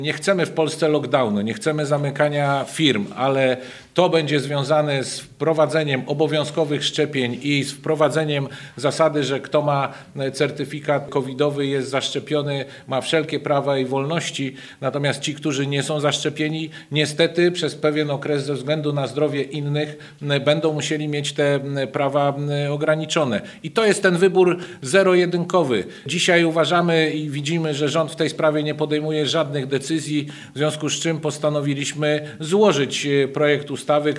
Dzisiaj widzimy, że rząd w tej sprawie nie podejmuje żadnych decyzji, w związku z tym postanowiliśmy złożyć projekt ustawy w Sejmie – podczas konferencji prasowej mówił Dariusz Wieczorek (wiceprzewodniczący Nowej Lewicy)